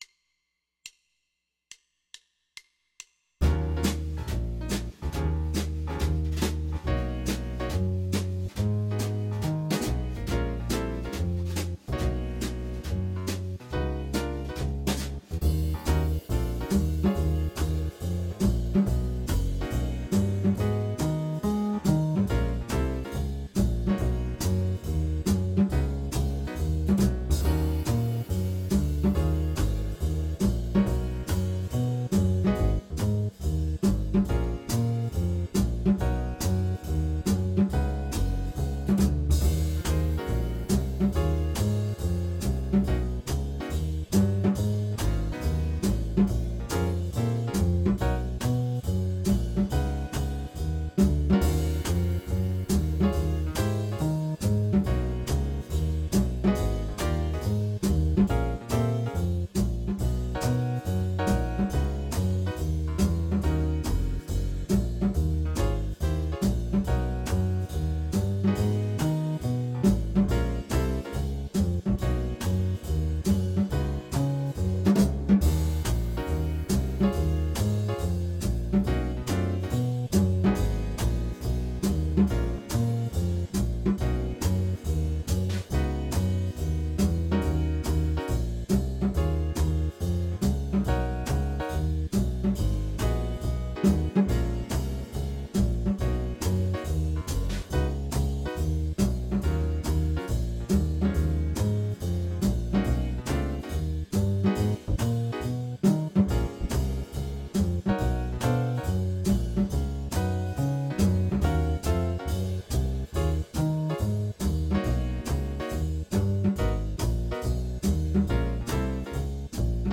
Ebm / D#m